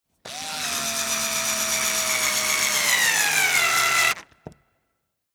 Akkuschrauber IXO 6
Schraube hineindrehen
58958_Schraube_hineindrehen.mp3